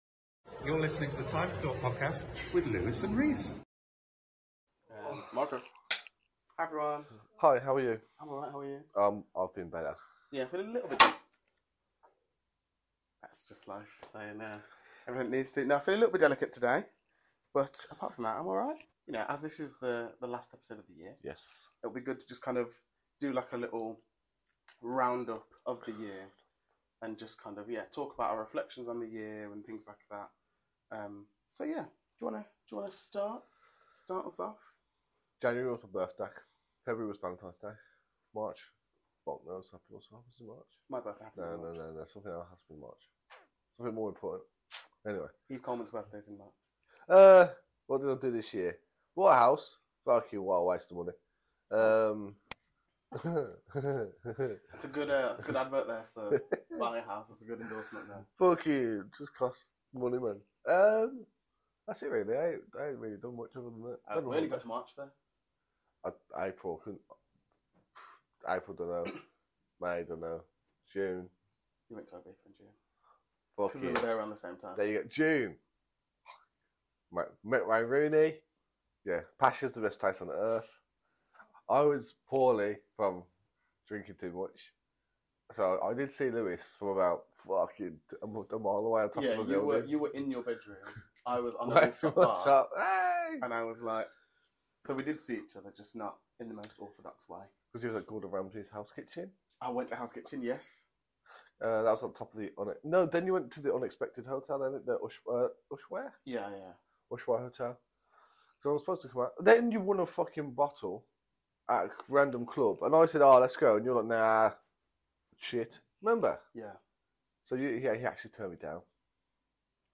Two mates, talking a bit of shit.